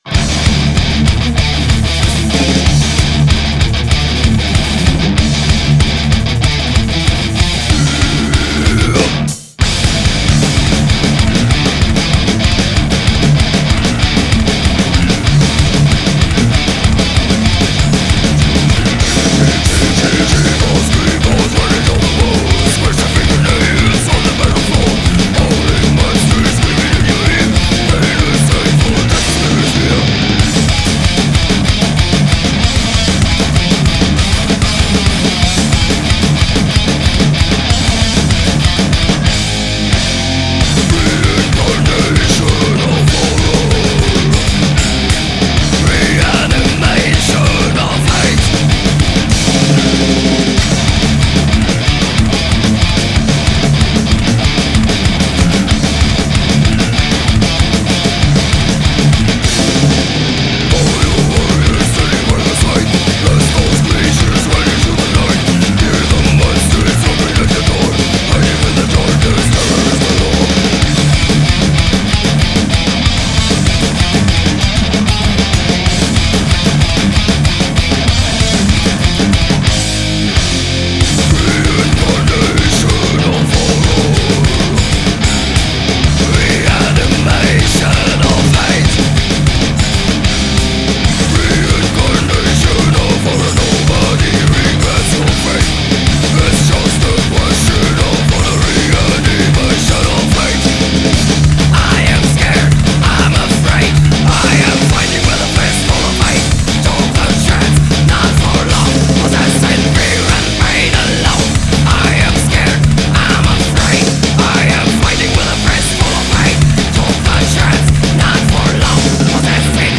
Vocals
Guitars
Bass
Drums